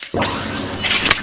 beam.ogg